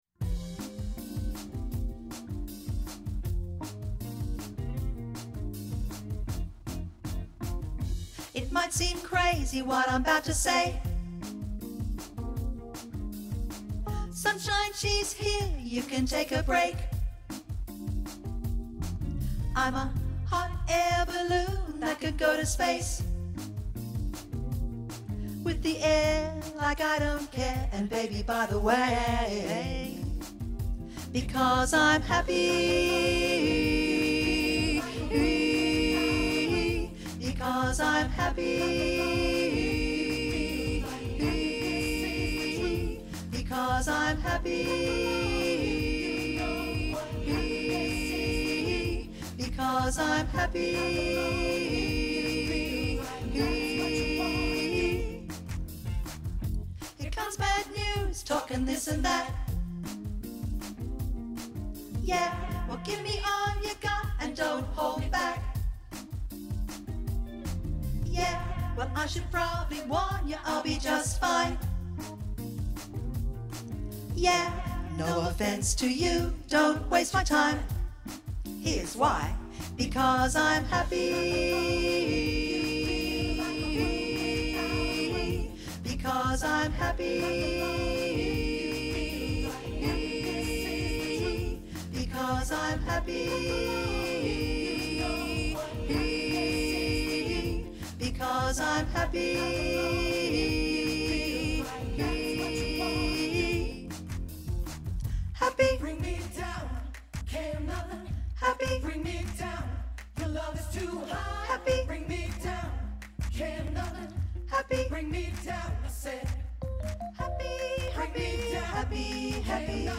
Vox Populi Choir is a community choir based in Carlton and open to all comers.
HappyAlto.mp3